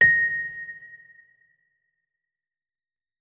46265b6fcc Divergent / mods / Hideout Furniture / gamedata / sounds / interface / keyboard / electric_piano / notes-70.ogg 31 KiB (Stored with Git LFS) Raw History